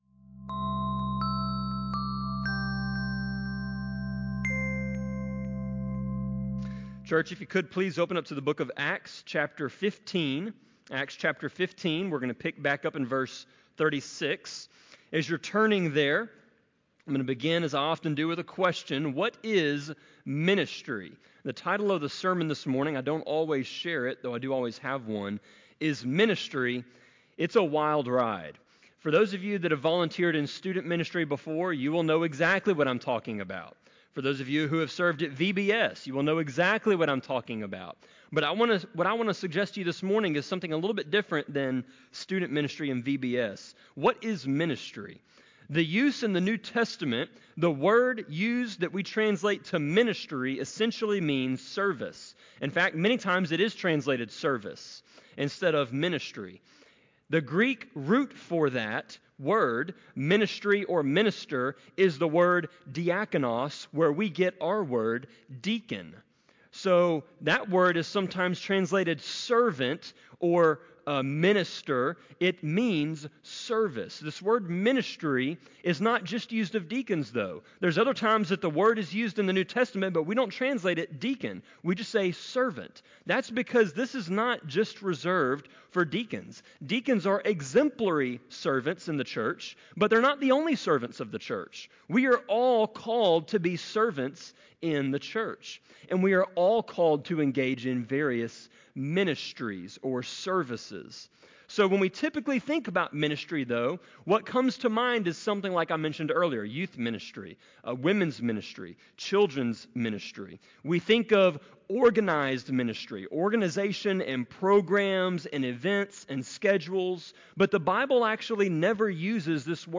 Sermon-24.7.14-CD.mp3